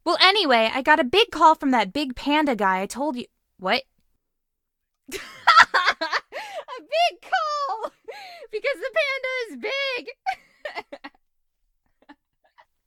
125 KB A Sample to show curious users how Lilac's voice sounds normally 1
Lilac's_Voice_Sample_1.ogg